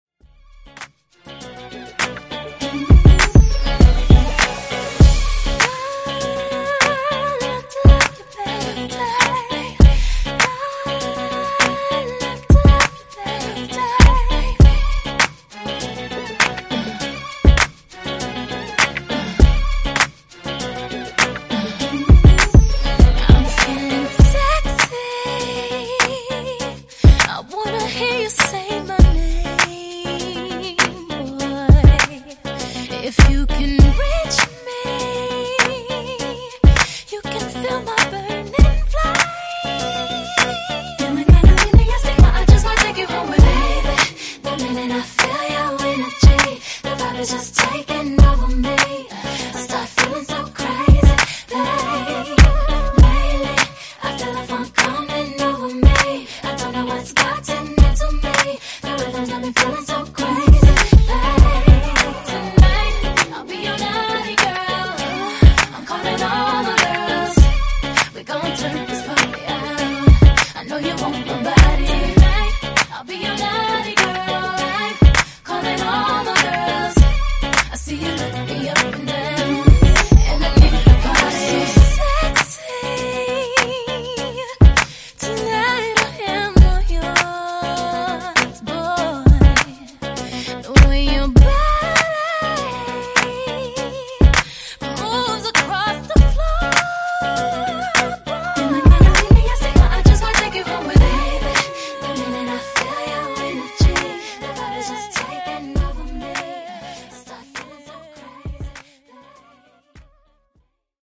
Genres: R & B , RE-DRUM Version: Clean BPM: 100 Time